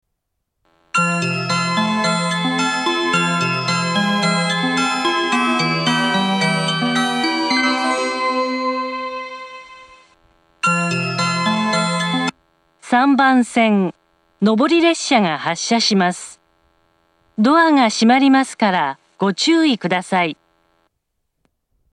列車の本数が少ないので駅員放送が入ることが多く、メロディー・放送に被ることも多いです。
３番線上り発車メロディー 曲は「花と空」です。